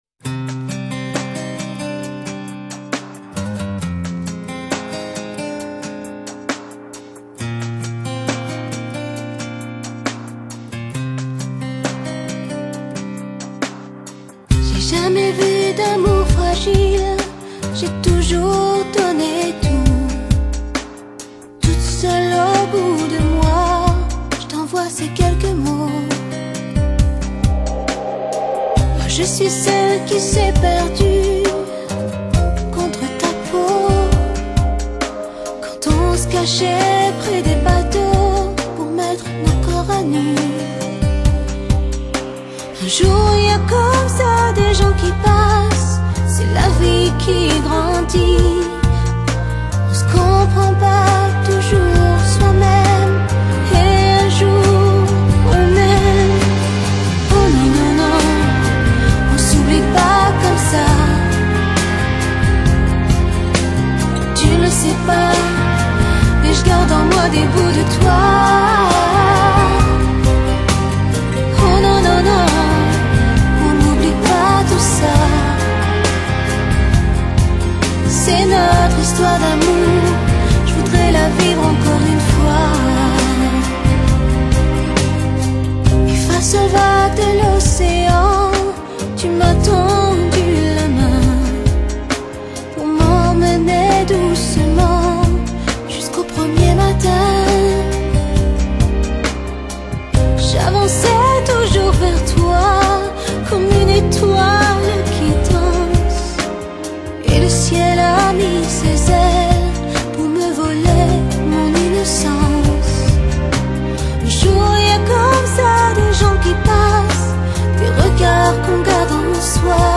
這歌有著拉丁的節奏，感覺很輕鬆！